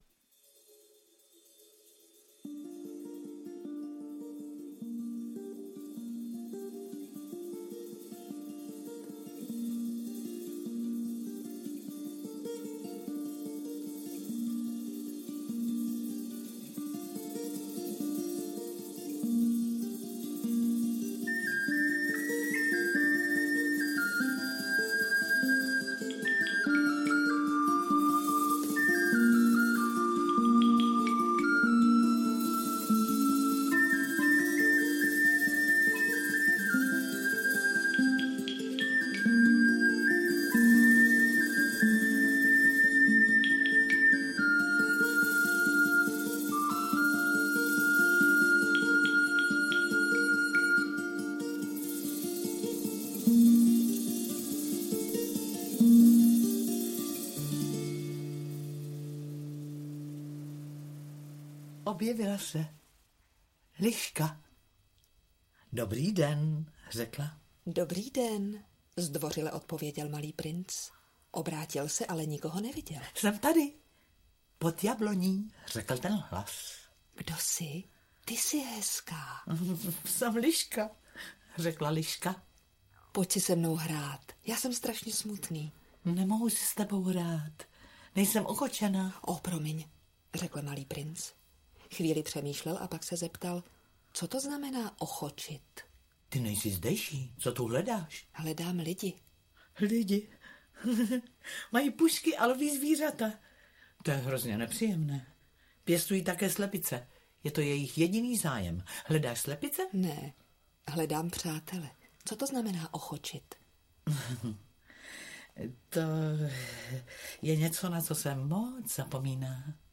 Vzpomínky Marie de Saint-Exupéryové a dopisy syna - Marie de Saint-Exupéry - Audiokniha
• Čte: Jiří Stivín